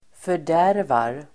Uttal: [för_d'är:var]